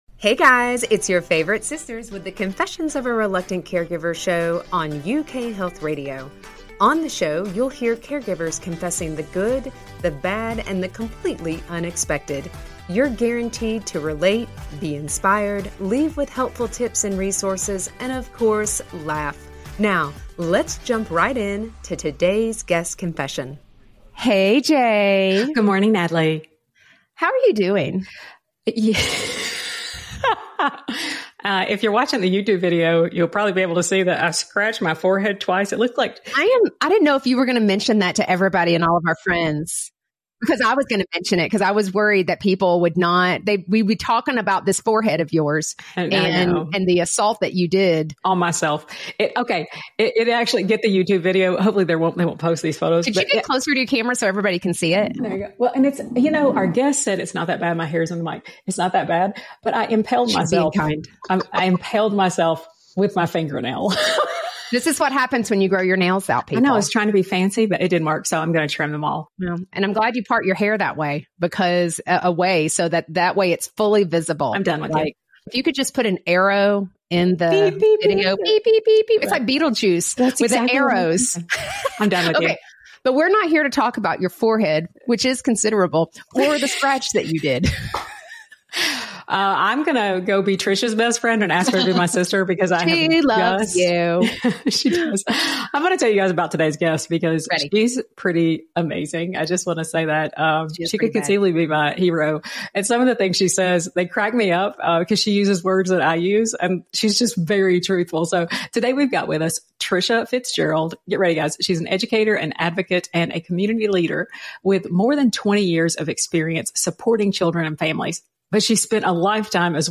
We are a trio of sisters supporting our mom who is living with Parkinson's disease, and a husband who survived cancer. We share the good, the bad, and the completely unbelievable of our caregiving journey.